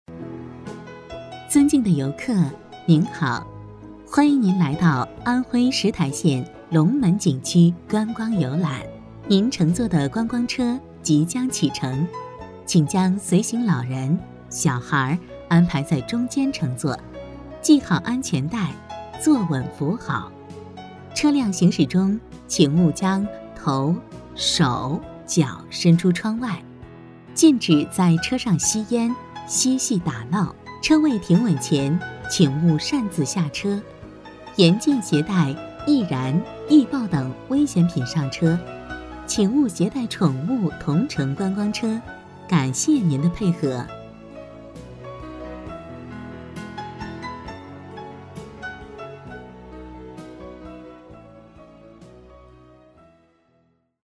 配音风格： 感情丰富，自然活力
【提示音】尊敬的游客